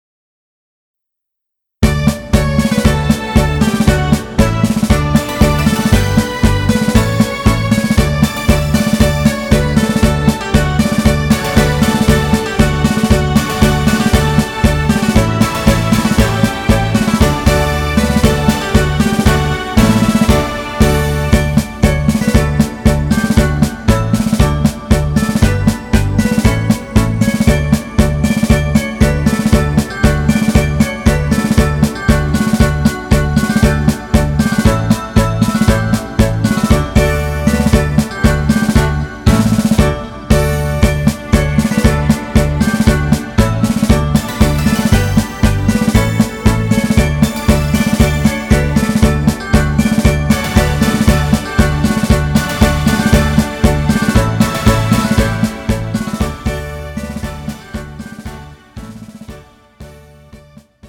음정 D 원키
장르 가요 구분 Pro MR